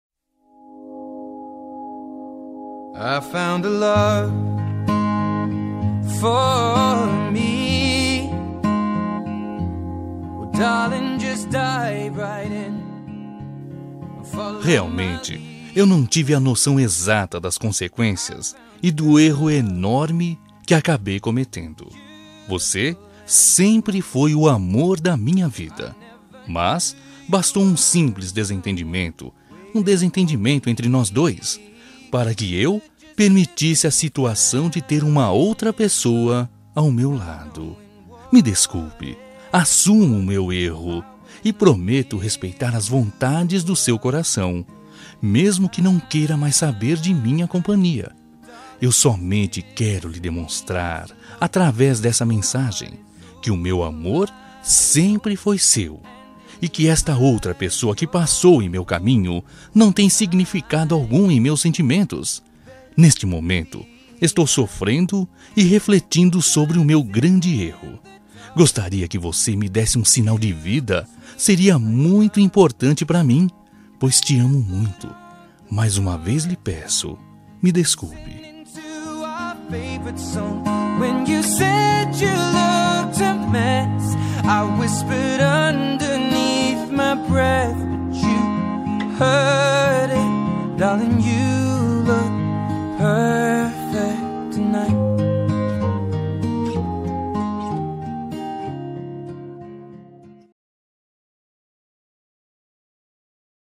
Telemensagem de Reconciliação Romântica – Voz Masculina – Cód: 945